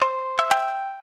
shamisen_ceg.ogg